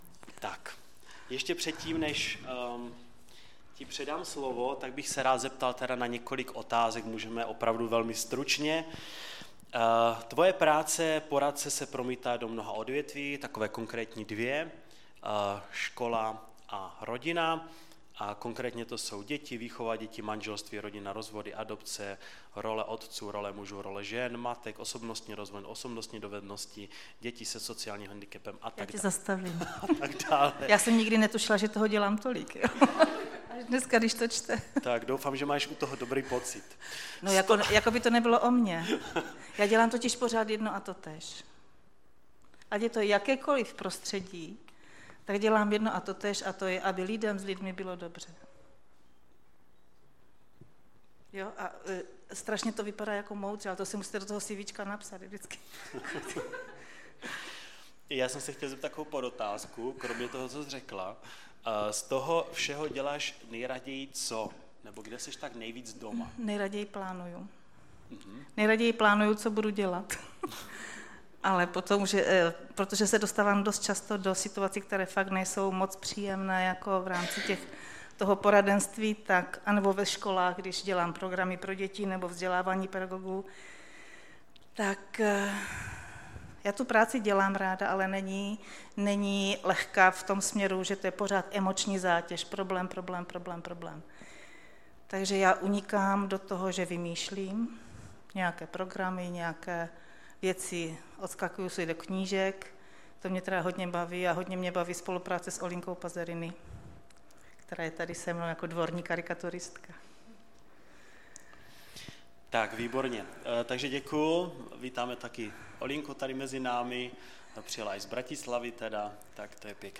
Typ Služby: Přednáška